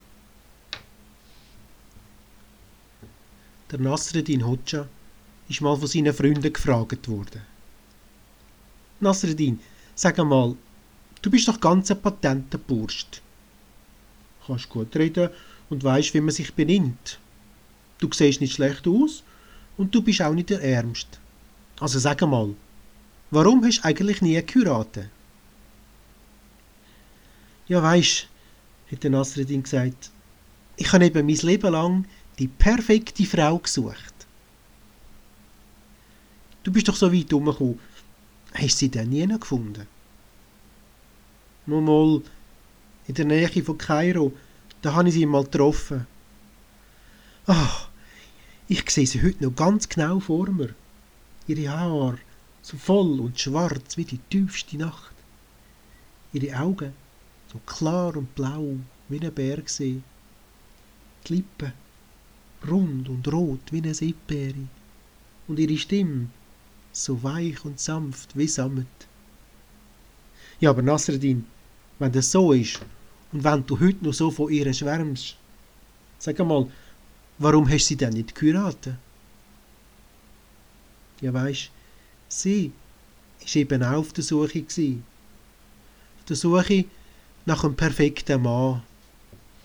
Ich erzähle frei und ohne Buch.
frischfrommfröhlichfrei und mit einem Augenzwinkern erzählt